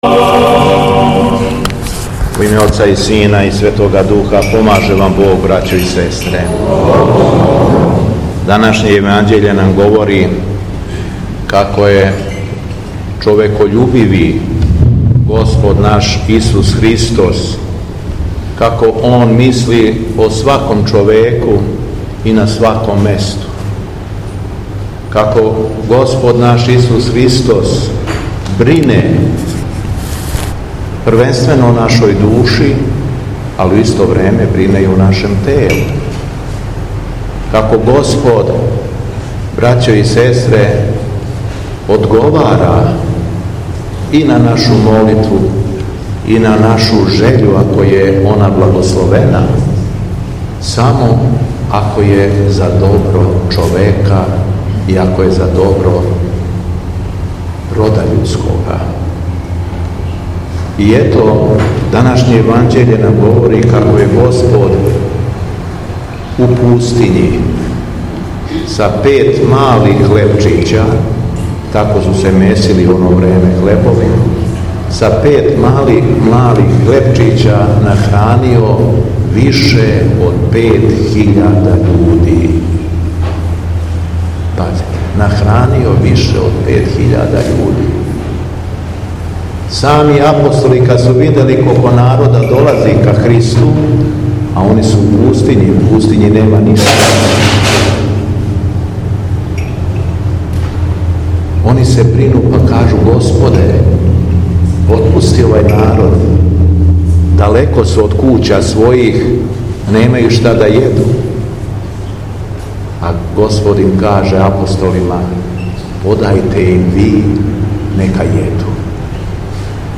Духовна поука Његовог Високопреосвештенства Митрополита шумадијског г. Јована